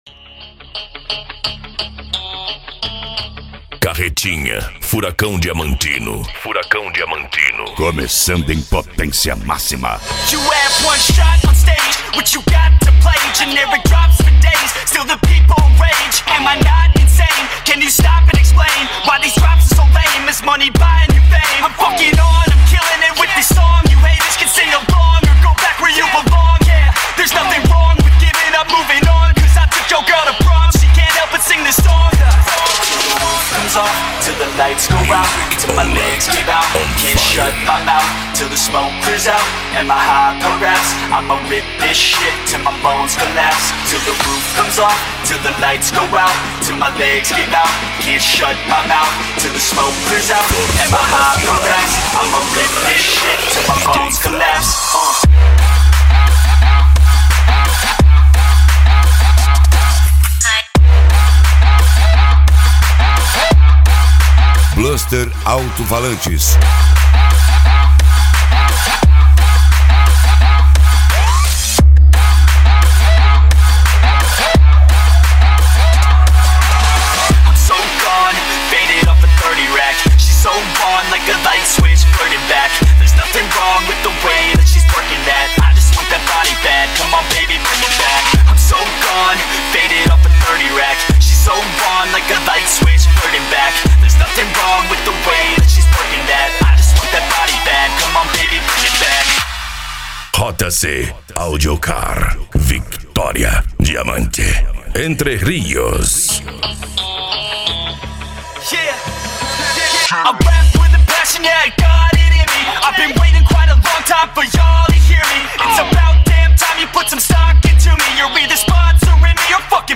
Bass
PANCADÃO
Remix
Trance Music